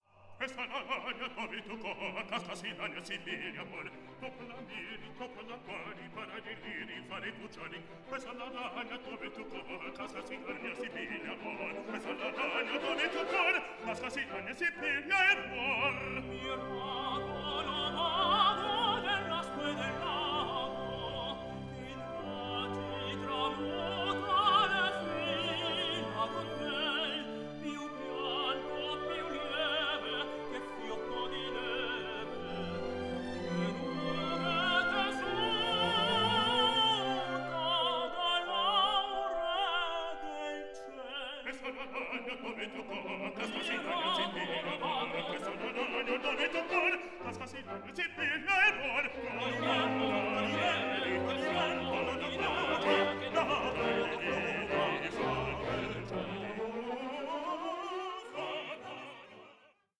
ambitious studio recording